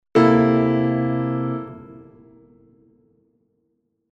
• Die große Sexte bzw. Tredezime (13) erzeugt den charakteristischen offenen Jazzklang und macht die Reibung von B♭ (Septime) und A (Tredezime) interessant
C(7)13 – Jazzakkord am Klavier
1. C7(13): Spielen Sie C und B mit der linken Hand und A mit der rechten Hand. Dieser C7(13) Akkord offenbart sofort den charakteristischen Jazzklang.